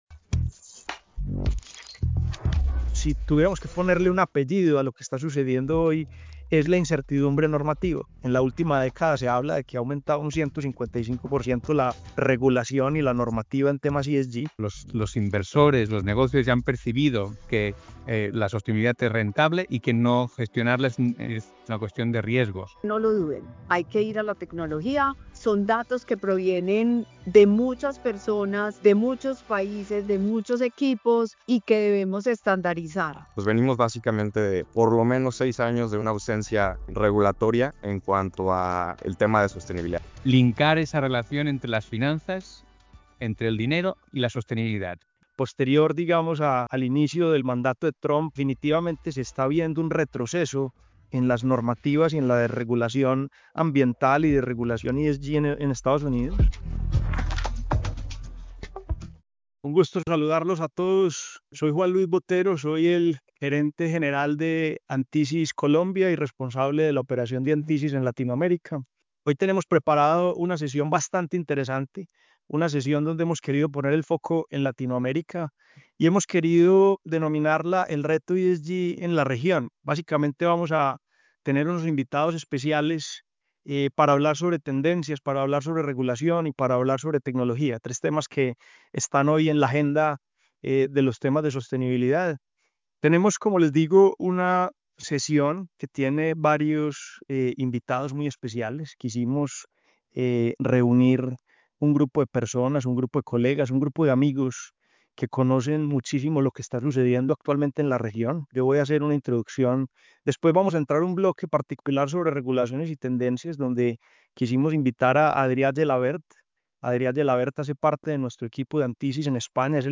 Las reglas del juego están cambiando, y la sostenibilidad dejó de ser opcional. En este episodio, revivimos los momentos más destacados del webinar qu...